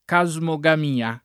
casmogamia [ ka @ mo g am & a ] s. f. (bot.)